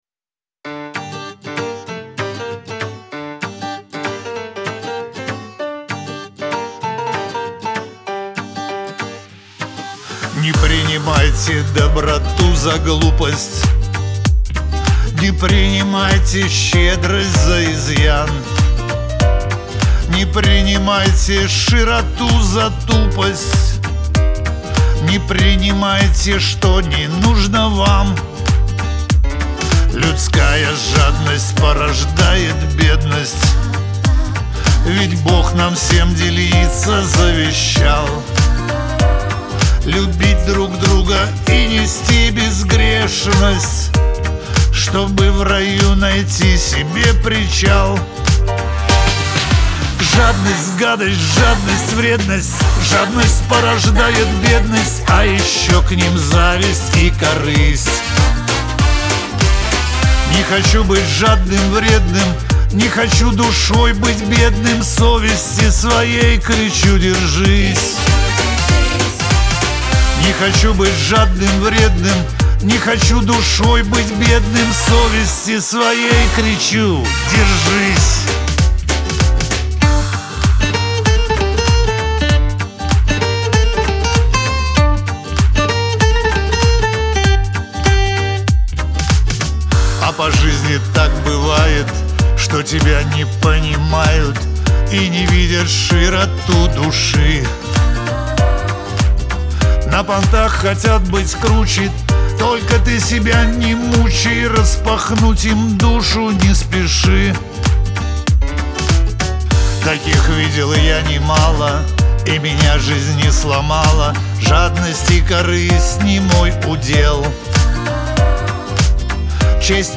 Шансон
Лирика
грусть